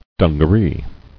[dun·ga·ree]